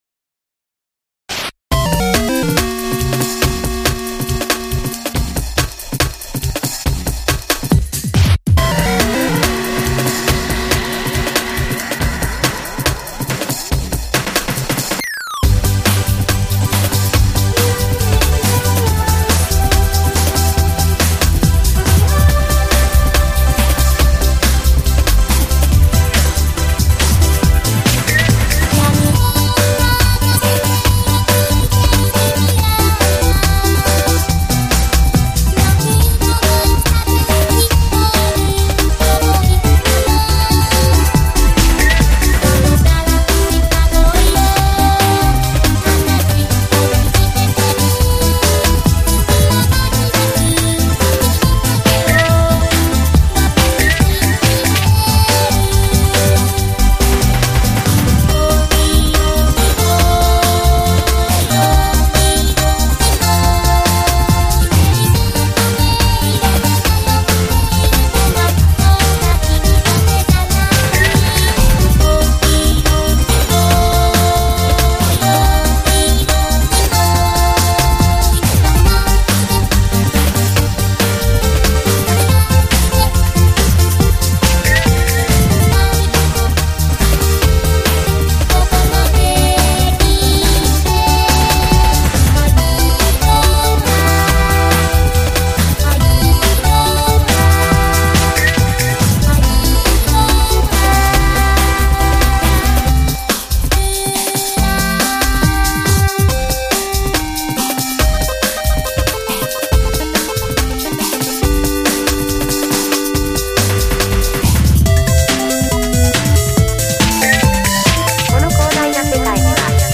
Magical 8bit Plug
その２つもVocaloidで再現したので